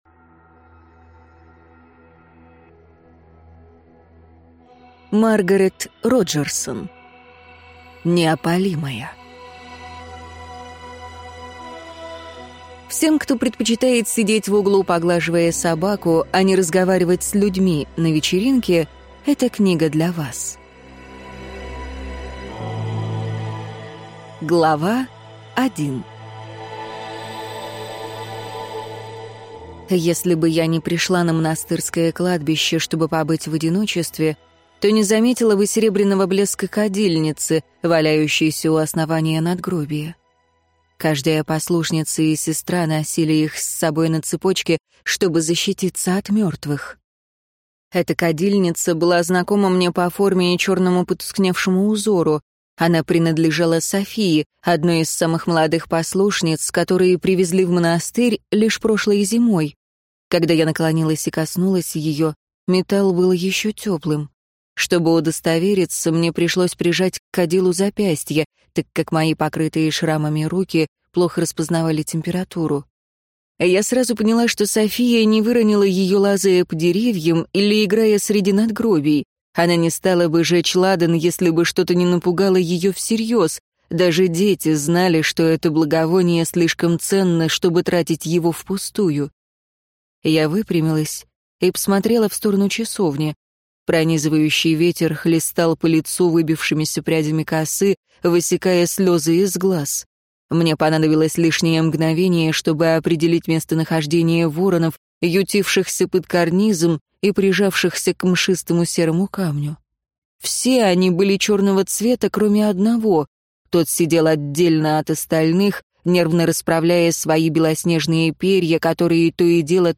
Аудиокнига Неопалимая | Библиотека аудиокниг